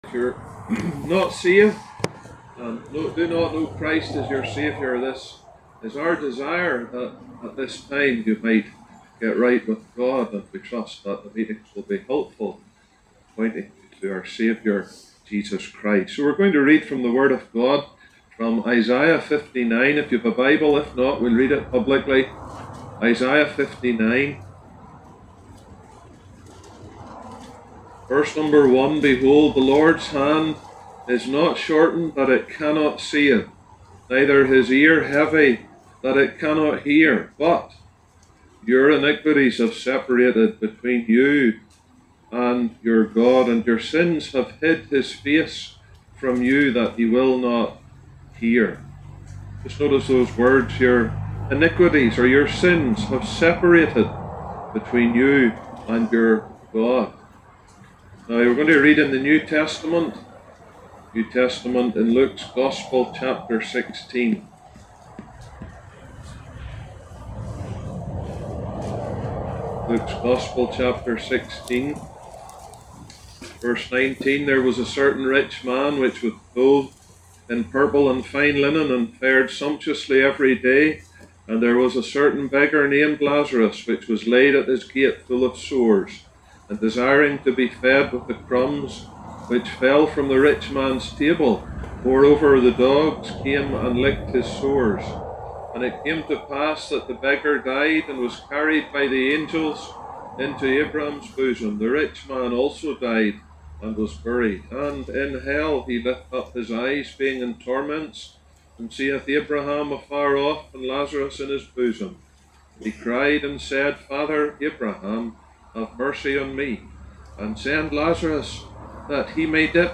Gospel Tent ’25: Between you and God (26 mins)